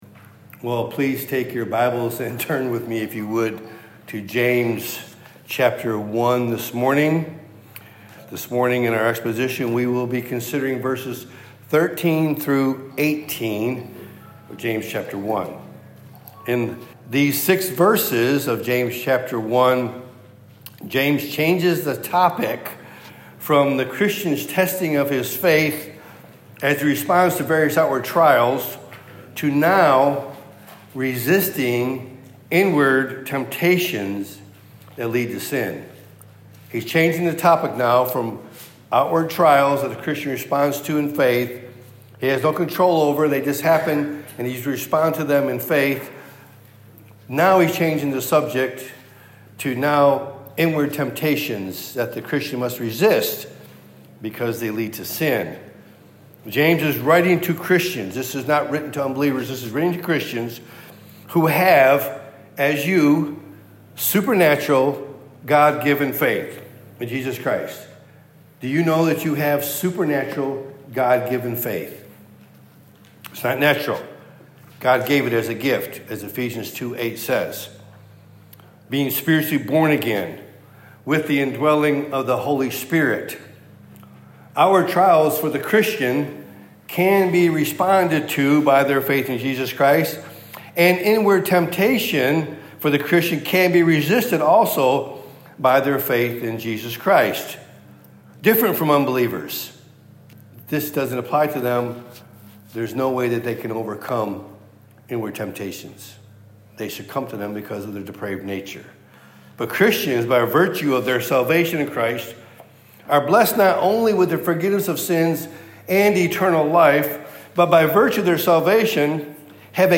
Topic: Sunday Morning